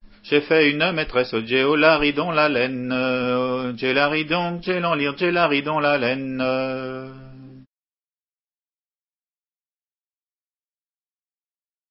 Entendu au festival des "Assemblées gallèses" en juillet 90